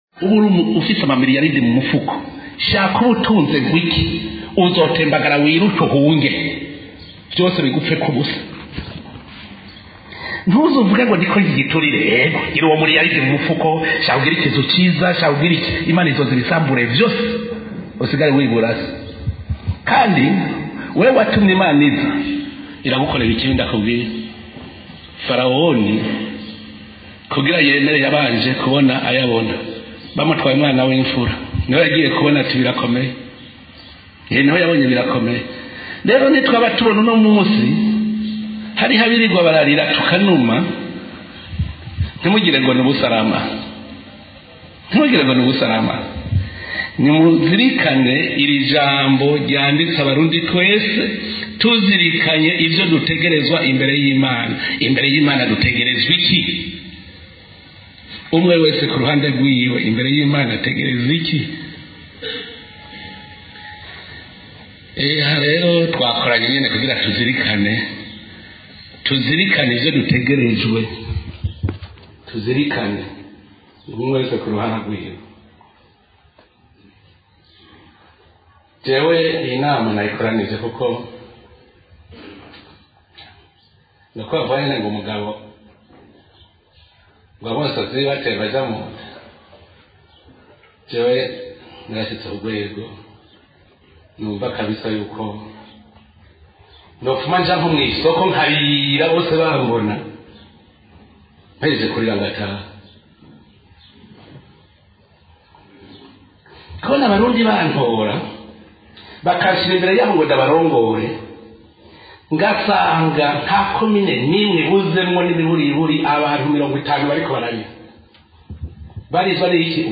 Mu kiganiro umukuru w’igihugu Evariste Ndayishimiye yagirishije abarongoye ubutungane mu Burundi kur’uno wa kabiri igenekerezo rya 24 myandagaro 2021,